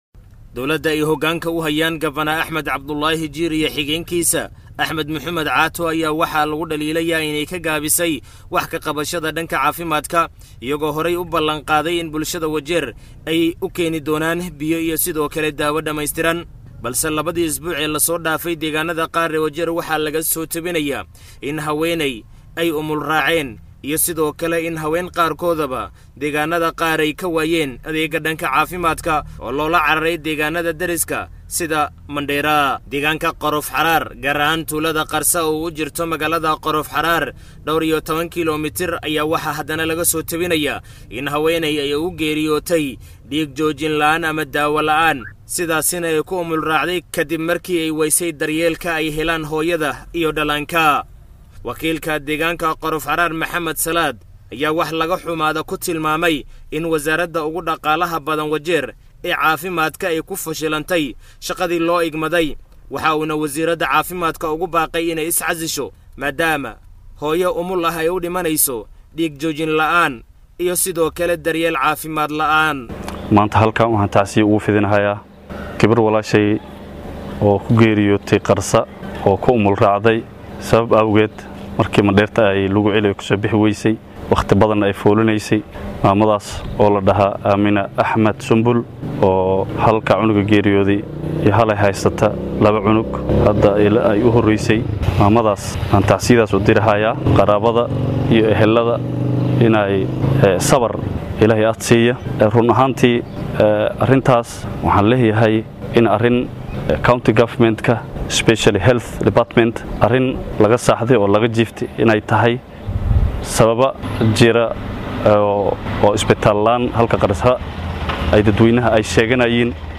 Wakiilka laga soo doortay deegaanka hoose ee Qorof Xaraar ee ismaamulka Wajeer oo u warramay warbaahinta Star ayaa faahfaahin ka bixiyay haweeney la sheegay inay geeriyootay maadaama ay wayday daryeel caafimaad.